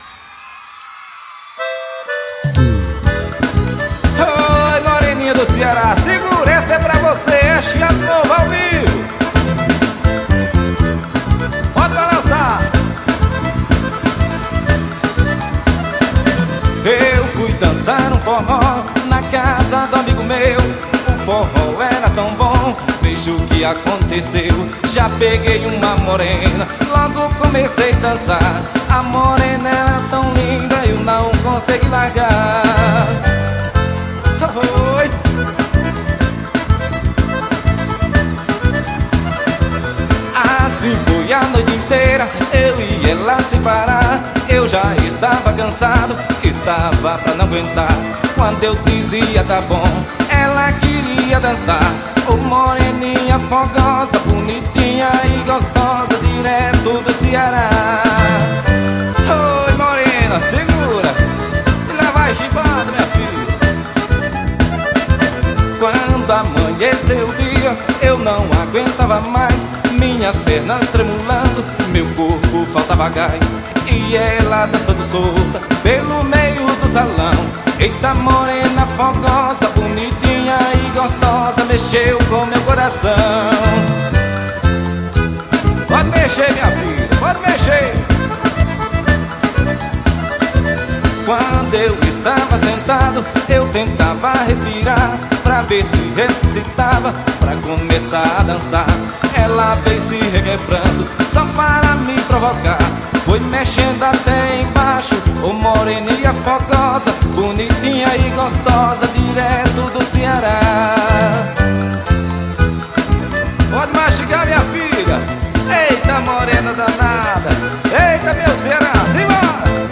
SHOW AO VIVO.